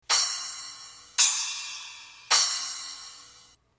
DRUM & DRUMMER
Ears to these 6-to-12-inch cymbals that share their name with a Darryl Hannah film;